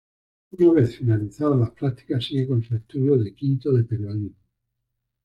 Pronounced as (IPA) /ˈpɾaɡtikas/